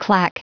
Prononciation du mot clack en anglais (fichier audio)
Prononciation du mot : clack